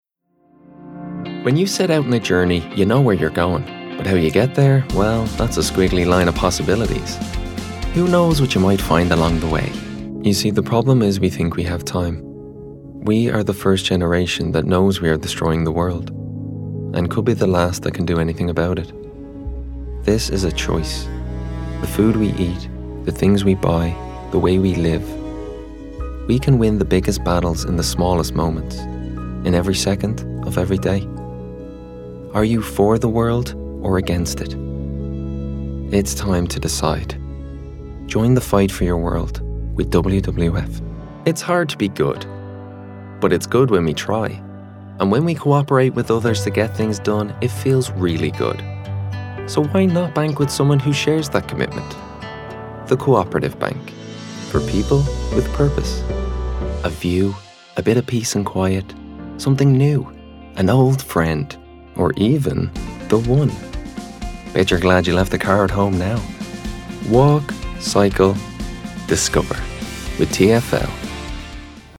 Male
20s/30s, 30s/40s
Irish Dublin Neutral, Irish Neutral